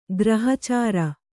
♪ grahacāra